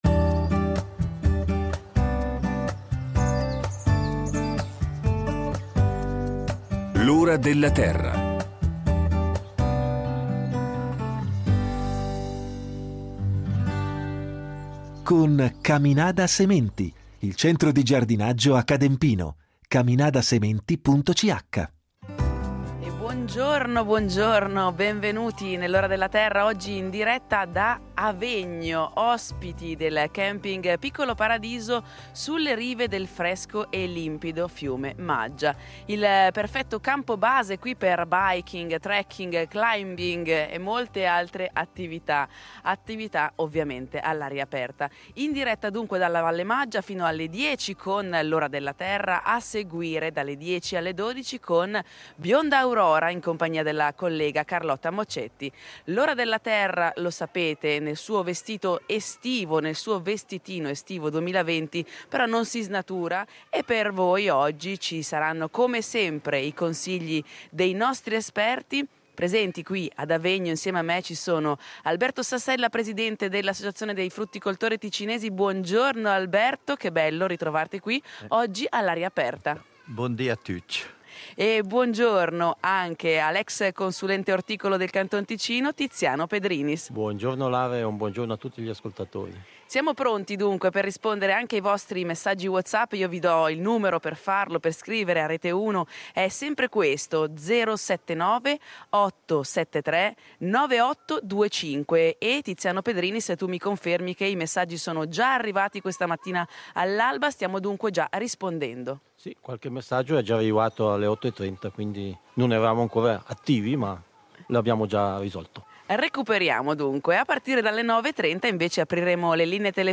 L’ora della terra e Bionda Aurora, in diretta dal Campeggio Piccolo Paradiso di Avegno , per una puntata all’insegna della Valle Maggia. Con gli esperti de L’Ora della Terra e ProSpecieRara .